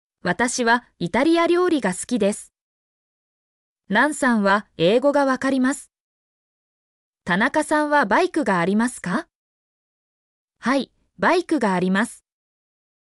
mp3-output-ttsfreedotcom-67_KkacX7EE.mp3